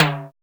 626 TIMB LO.wav